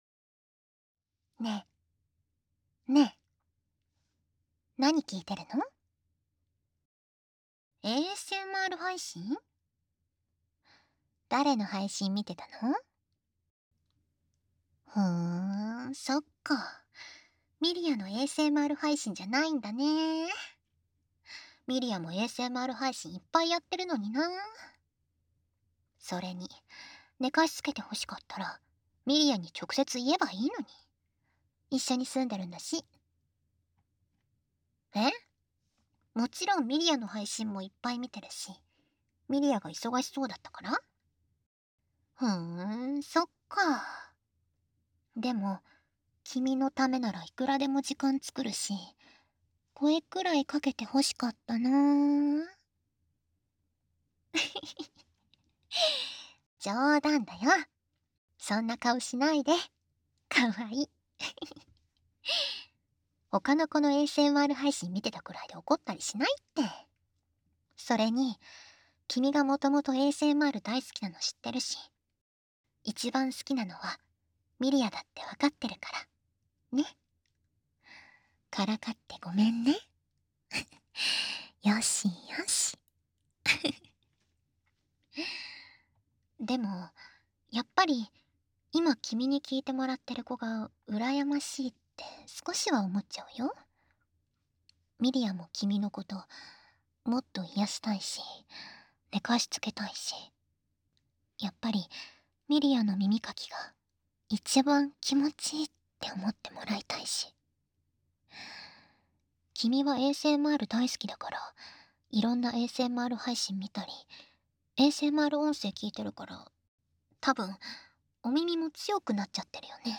【爆音推奨】脳みそに響く!?ず～っと両耳から強めに責められちゃう?
ASMR